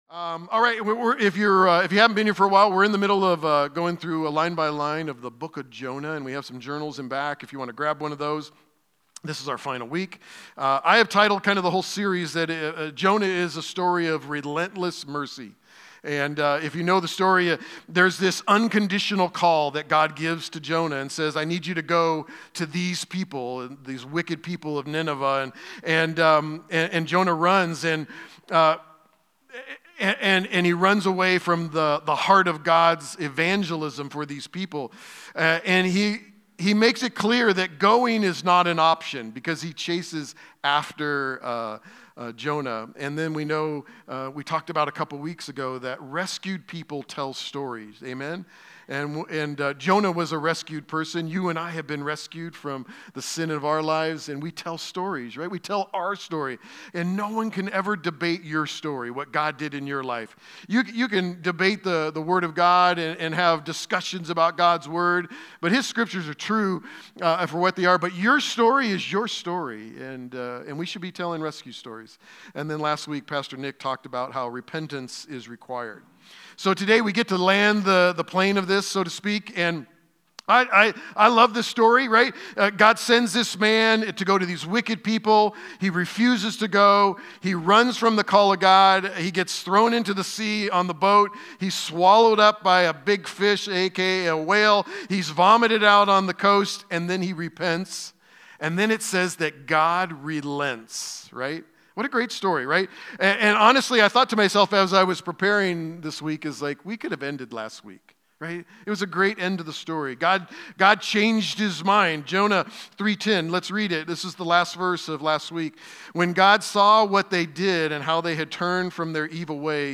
Sermons | Kingdom Church